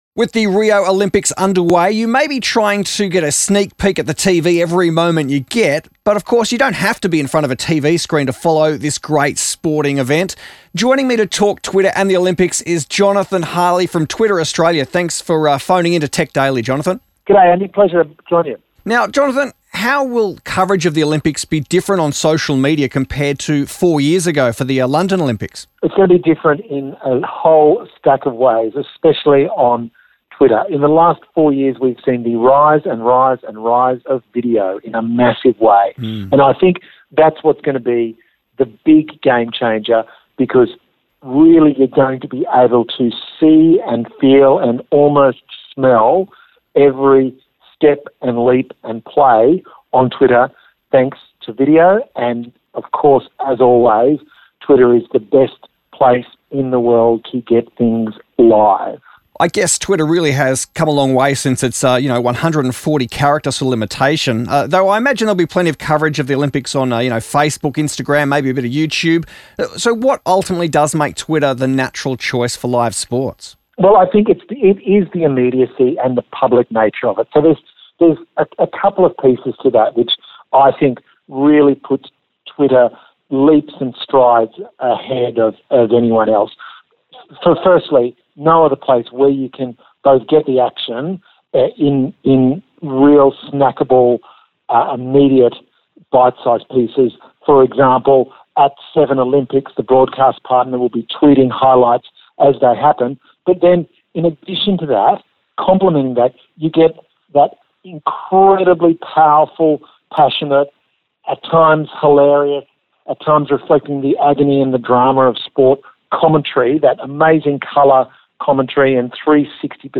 Twitter-and-the-Rio-Olympics_Interview.mp3